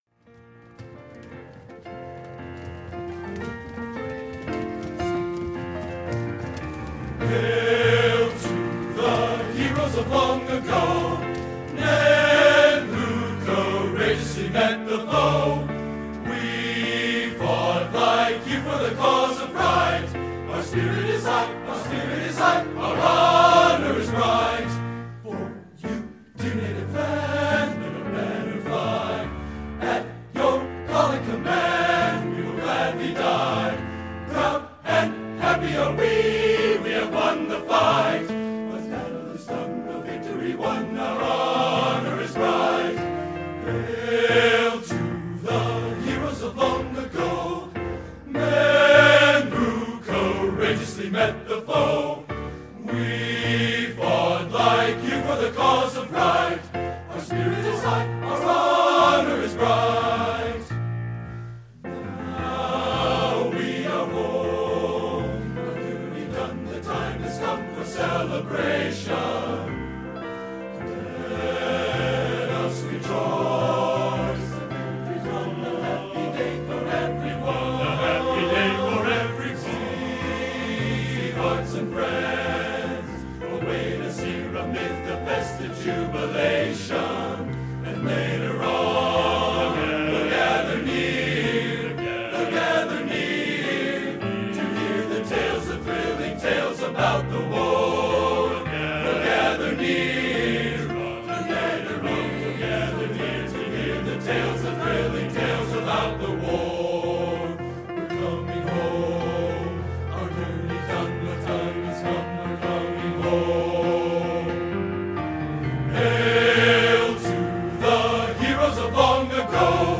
Genre: Opera | Type: